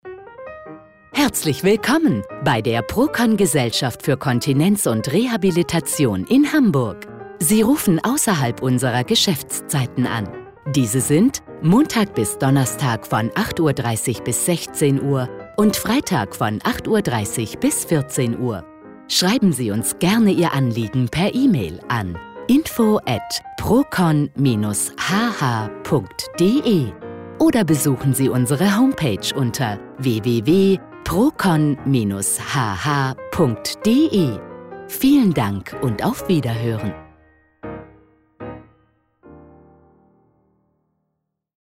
Anrufbeantworter Ansage ausserhalb der Geschäftszeiten:
Procon-Anrufbeantworter-1a.mp3